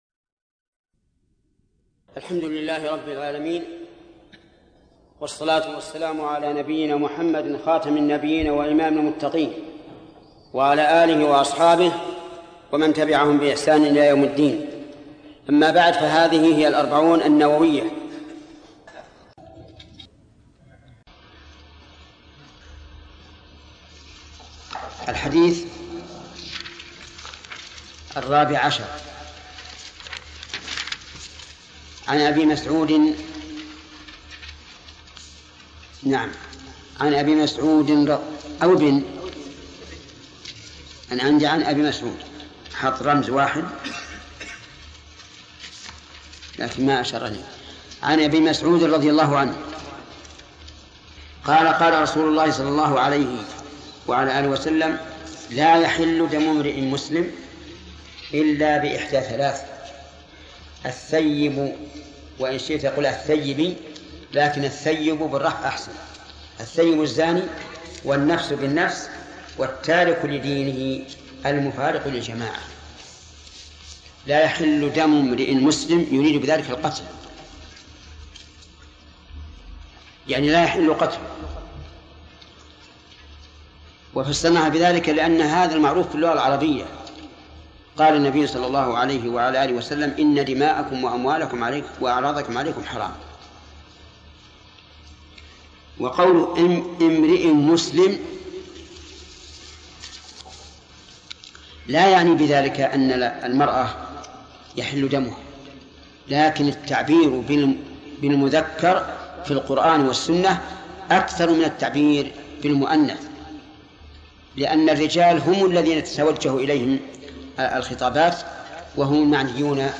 الدرس الثالث عشر : من قوله: "الحديث الرابع عشر"، إلى: نهاية الحديث الرابع عشر.